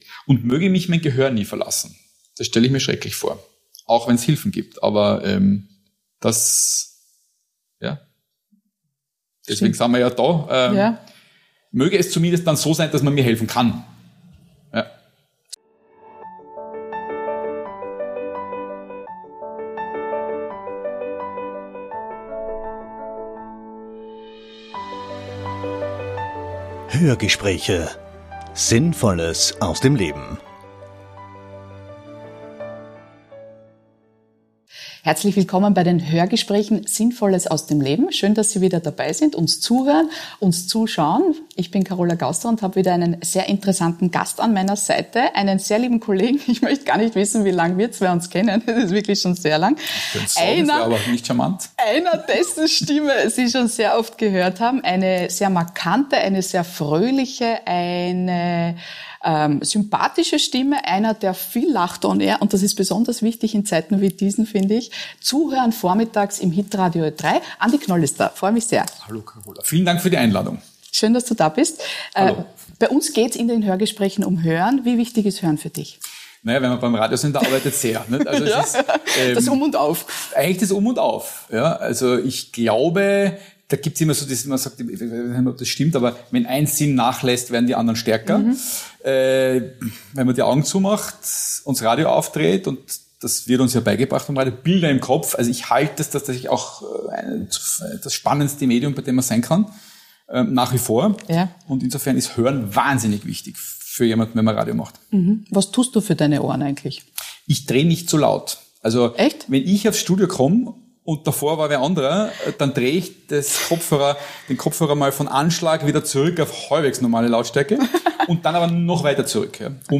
#15 - Andi Knoll - Ein erfrischendes Gespräch mit dem bekannten Radio Moderator ~ Hörgespräche Podcast
Andi Knoll, der Mann mit der Gute Laune-Stimme von Ö3 weiß, wie wichtig es ist, sein Gehör zu schützen, auch wenn er zugibt, dass laute Musik manchmal schon auch der Seele guttut.